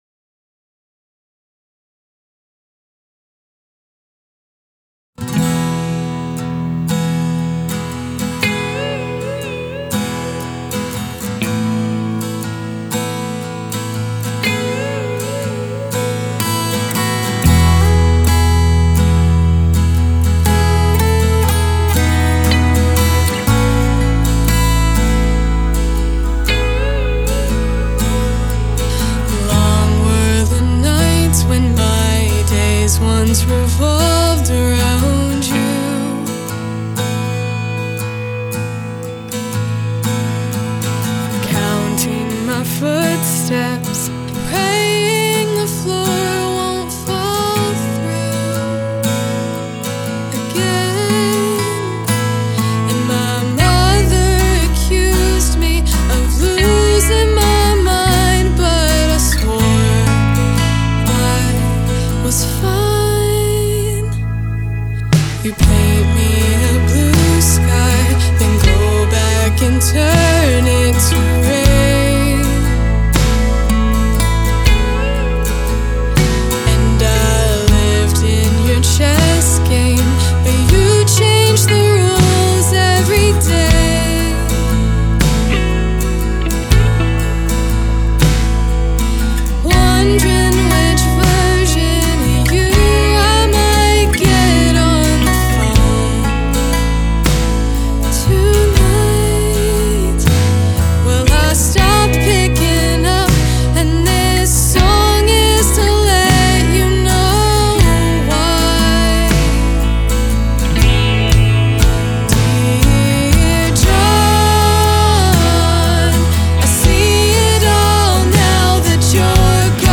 She has a beautiful voice.